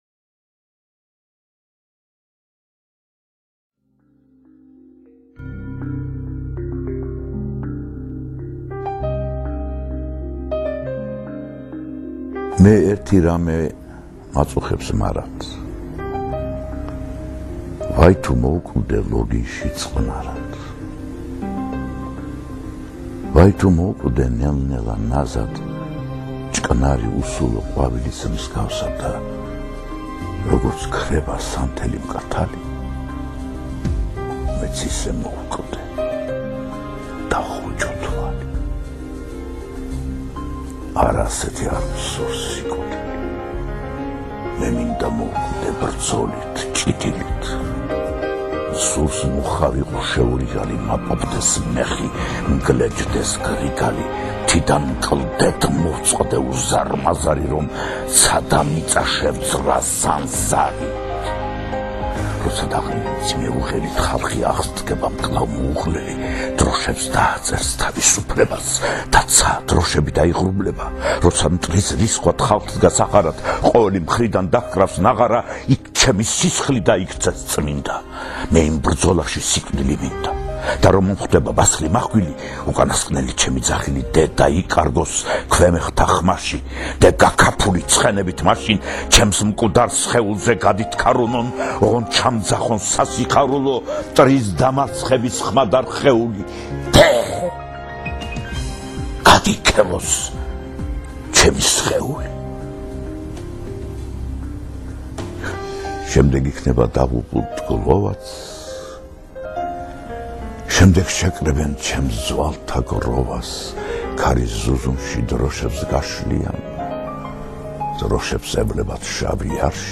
ასეა... ყველა ცოცხლობს, ყველა კვდება... მაგრამ, ზოგიერთნი არა კვდებიან თურმე... ისინი სიცოცხლეს აგრძელებენ ისევ ჩვენს გვედით, ჩვენს ფიქრებში და ჩვენს ყოველდღიურობაში... მისი ბოლოს წაკითხული ლექსი მოვისმინე... ვერ გადმოგცემთ როგორი სიამაყე დამეუფლა ამის შემხედვარეს... 84 წლის ჭეშმარიტი არტისტი როგორი ცეცხლითა და როგორი ჟინით კითხულობდა  „ჩვენ შეიძლება ბრძოლაში მოვკვდეთ, მაგრამ არც მაშინ ვტოვებთ სიცოცხლეს“-ო... რა ბედნიერებაა ასეთი ქართველის ეპოქაში რომ მომიწია ცხოვრება!
აი ისინიც: კონსტანტინე გამსახურდია, გალაკტიონი, გოგლა, აკაკი, უშანგი ჩხეიძე, გრიგოლ რობაქიძე და სხვა... მადლობა თანამედროვეობას, რომ გვეძლევა საშუალება ამ ძვირფას ხმათა აუდიოთეკის შემონახვისა. სწორედ ამ ხმათა შორისაა გურამ საღარაძე, აბა ნახეთ, მოისმინეთ, შეიგრძენით, თუ როგორი ჟღერადი, თანმიმდევრული, მუსიკალური და საოცარია მის მიერ წარმოთქმული ყოველი სტროფი, სიტყვა თუ ფრაზა... მერე და რა მიმიკა, ხელის მოძრაობათა რა ზუსტი და მკვეთრი მიმოხრა... ხმის ტემბრი, გრადაცია, მზერა, ინტონაცია, აფეთქება, პაუზა, დუმილი....